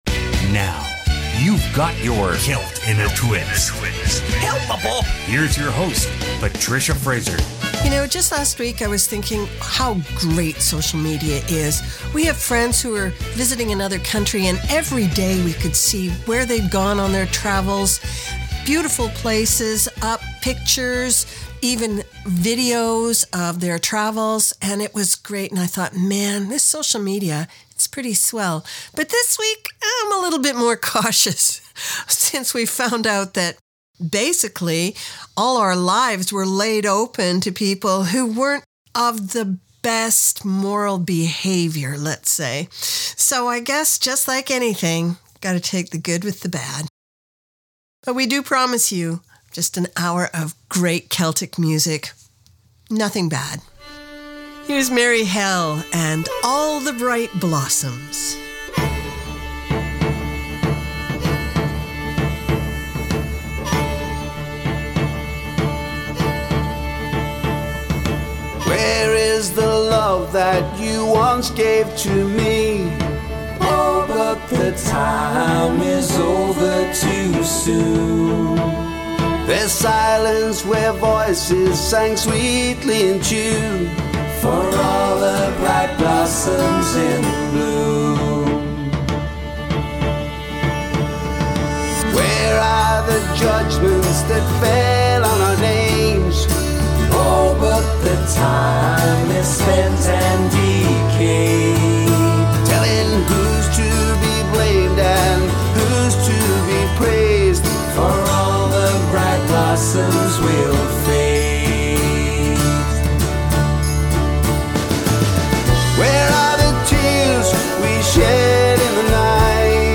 Canada's Contemporary Celtic Radio Hour